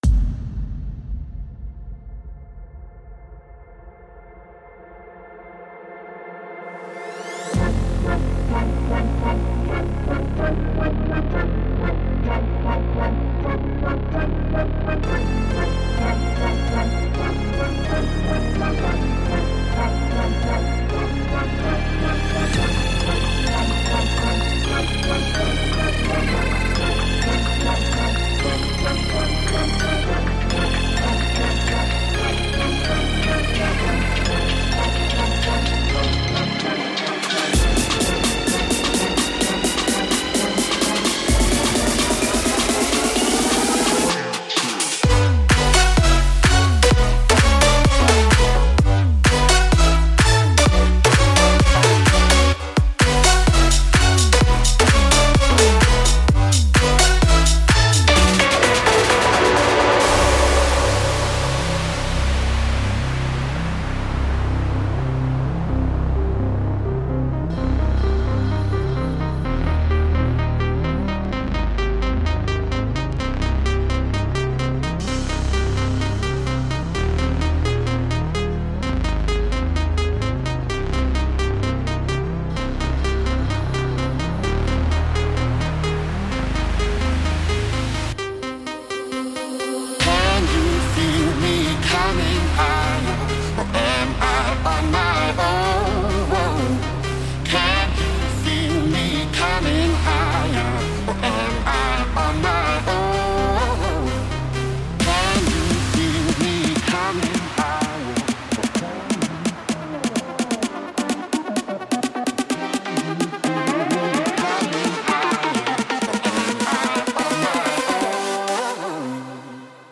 包含Drop MIDI工具，精心制作的打击，FX，鼓循环和鼓音的样本包